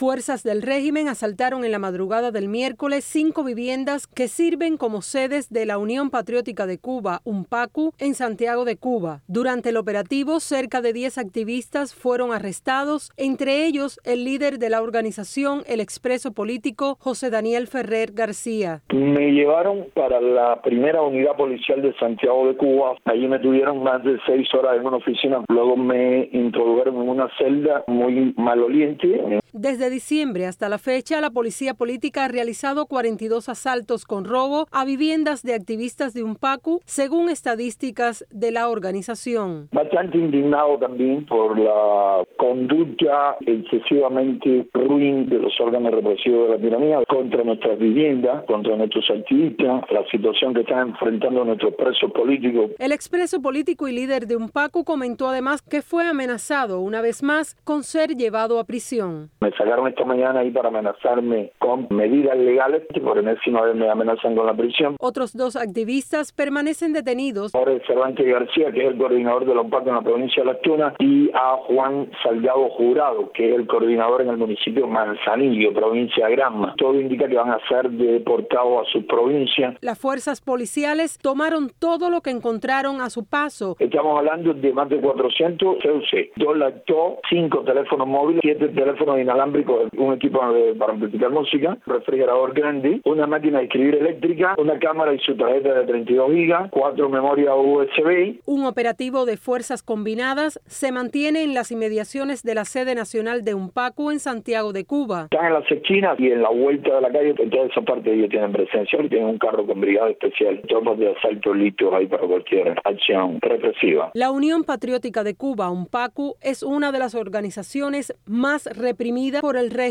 Declaraciones de José Daniel Ferrer